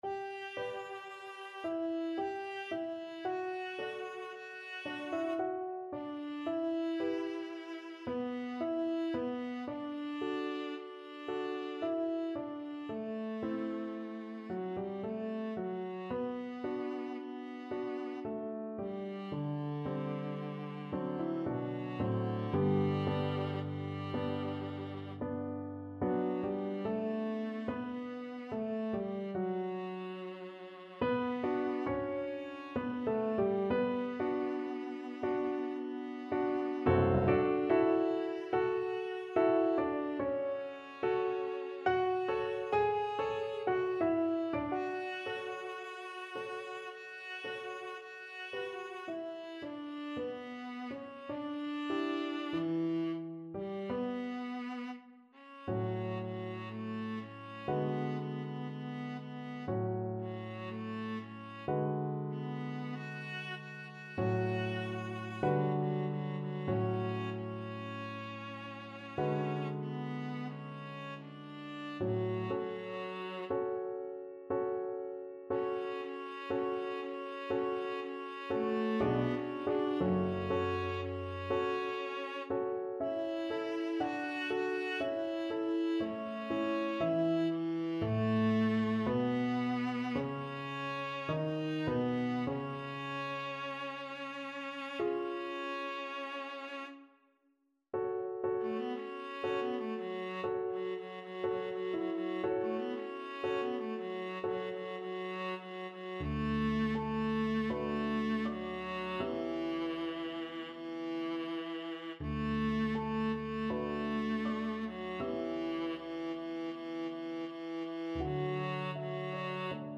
Andante molto calmo = 56
3/4 (View more 3/4 Music)
Classical (View more Classical Viola Music)